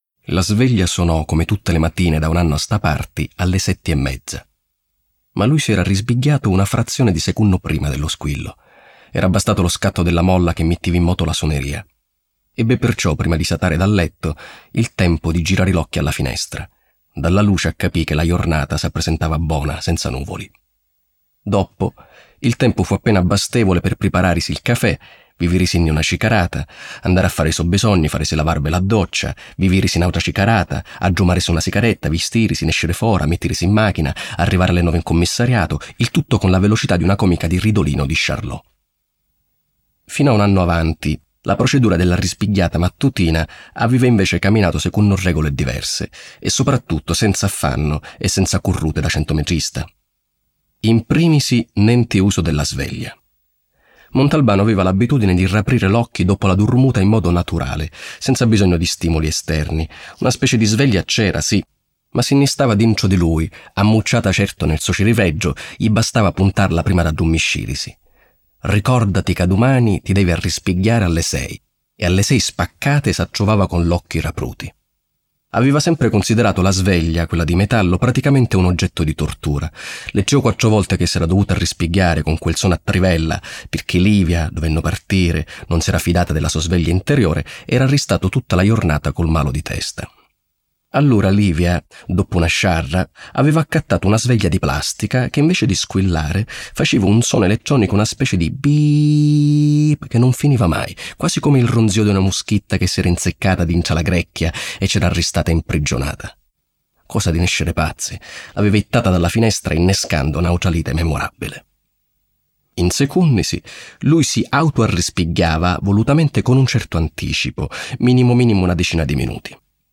letto da Luigi Lo Cascio
Versione audiolibro integrale
La calda e fascinosa voce di uno dei migliori attori italiani, Luigi Lo Cascio, racconta una torbida inchiesta del celebre commissario, un delitto spietato in una casa di periferia di Vigata e tutto sembra condurre alla pista passionale. Il commissario, tuttavia, non si lascia ingannare dalle apparenze.